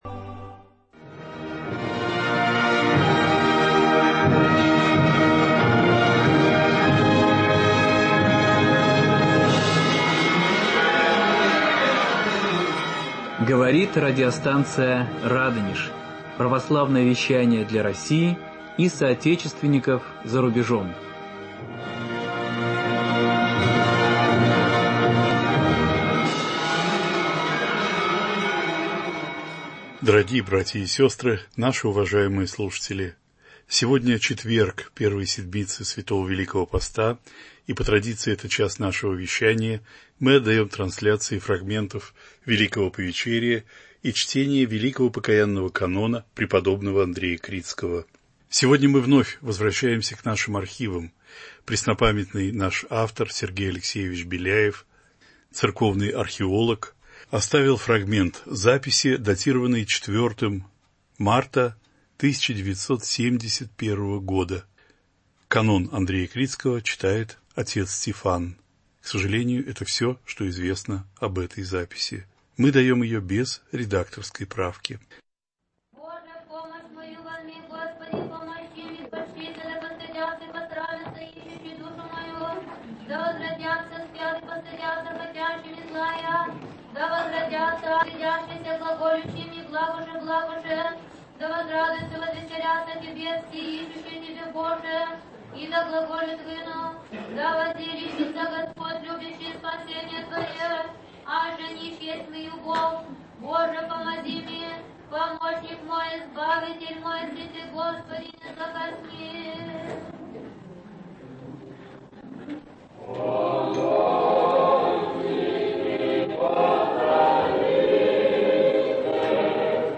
Слушать Скачать MP3-архив часа Трансляция фрагментов Великого покаянного канона преподобного Андрея Критского, читаемого в четверг Первой седмицы Великого Поста.